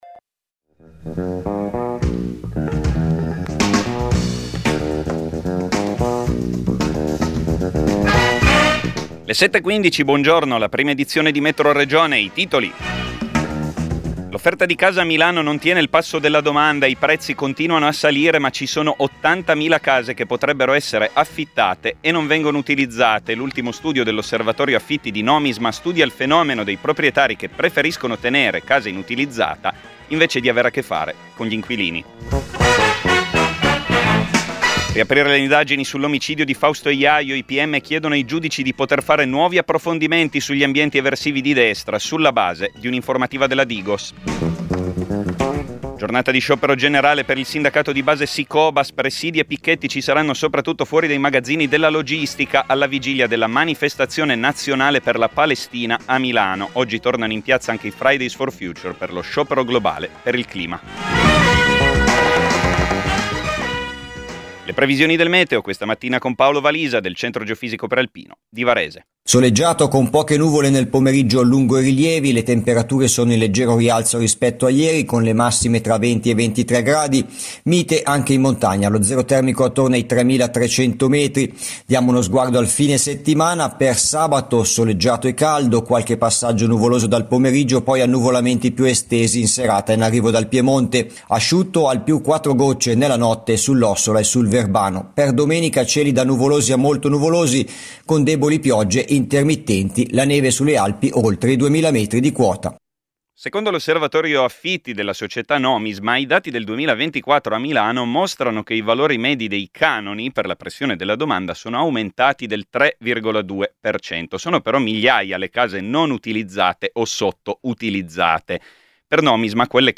Conferenza stampa per il corteo di sabato 12 a Milano - CUB